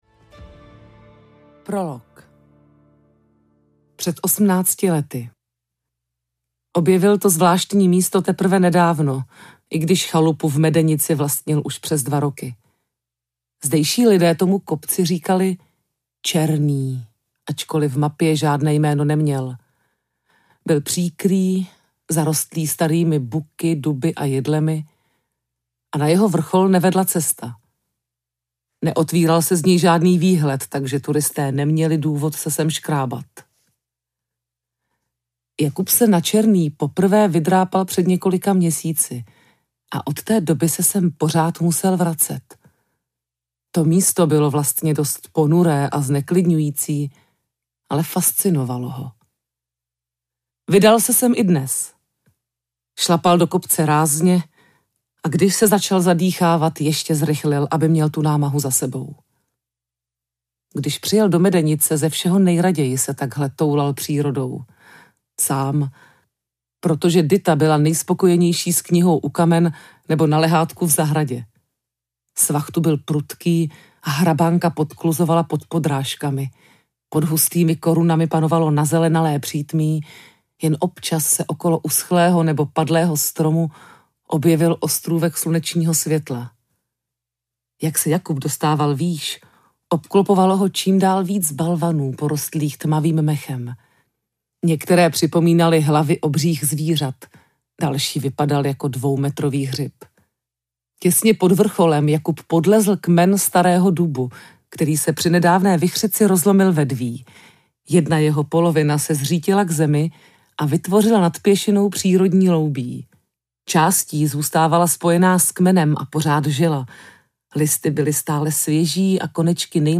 Letní slunovrat audiokniha
Ukázka z knihy
• InterpretTereza Kostková